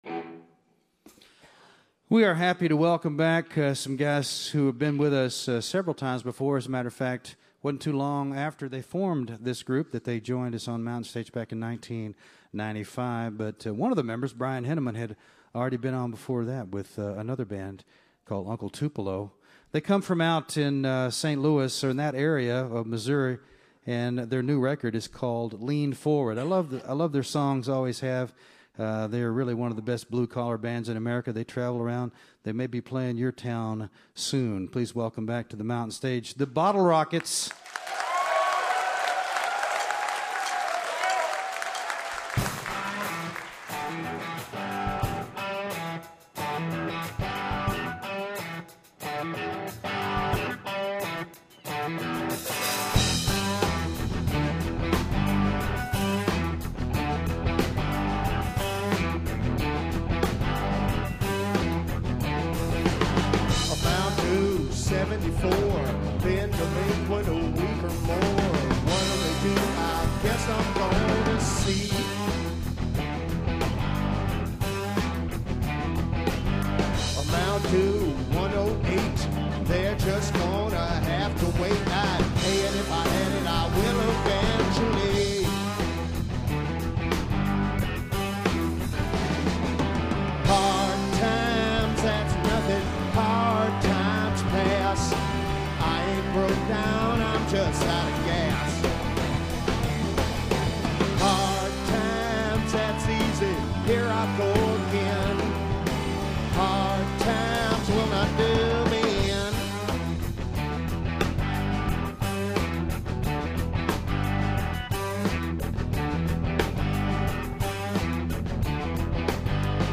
heartland rockers